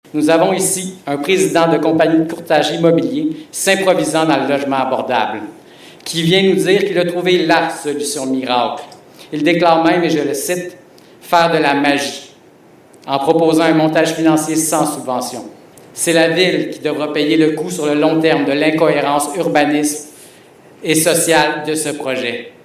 Un résident
au conseil d’arrondissement du Vieux-Longueuil.